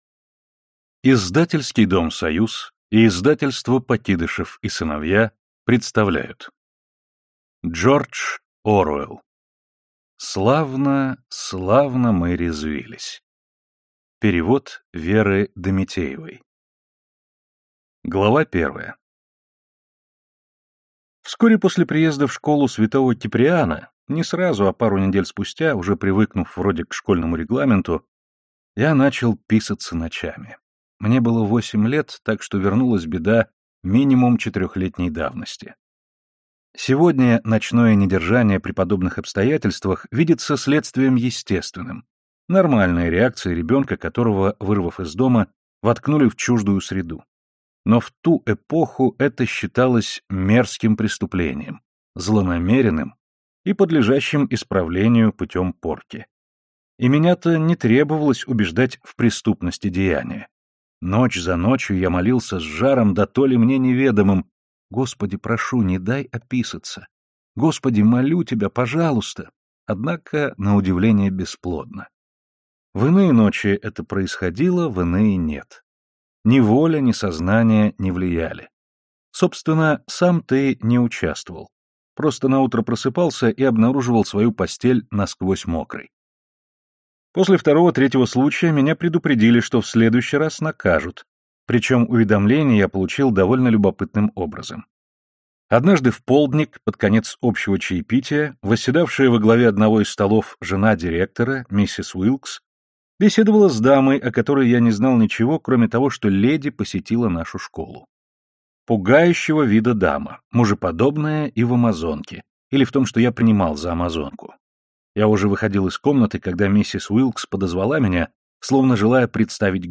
Аудиокнига Славно, славно мы резвились | Библиотека аудиокниг